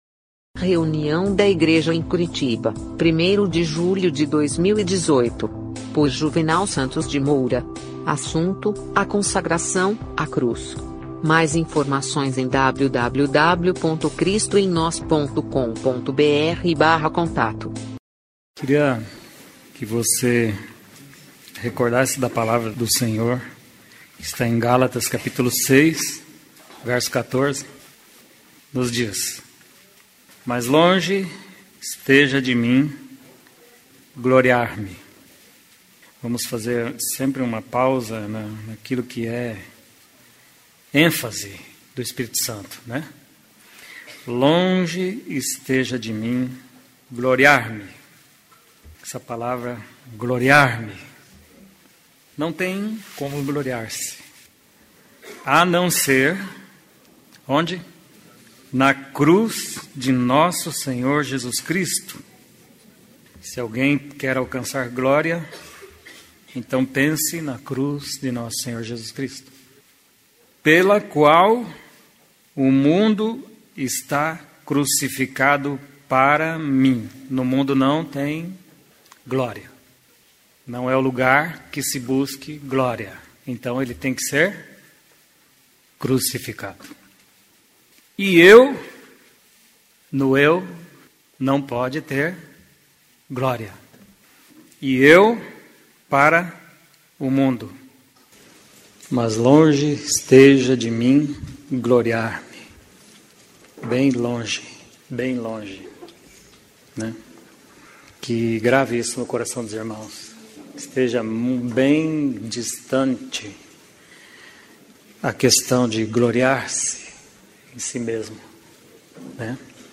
Mensagem
na reunião da igreja em Curitiba